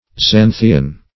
Xanthian \Xan"thi*an\, a.